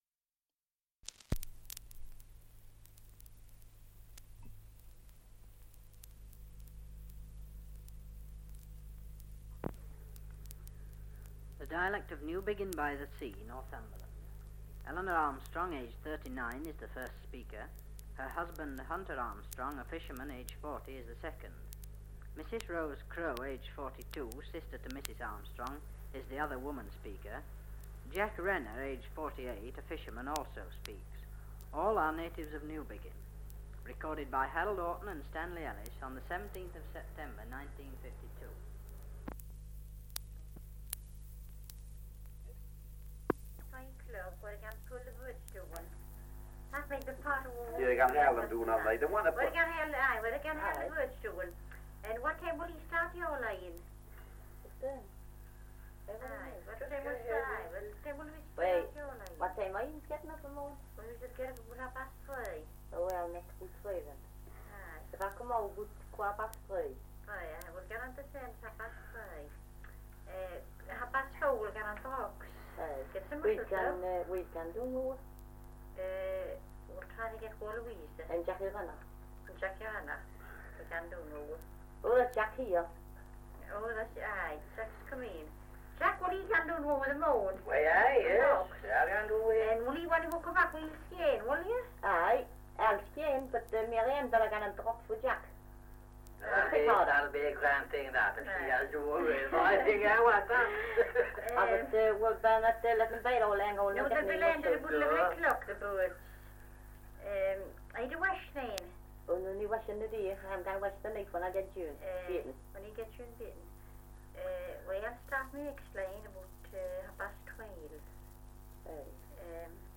Dialect recording in Newbiggin-by-the-Sea, Northumberland
78 r.p.m., cellulose nitrate on aluminium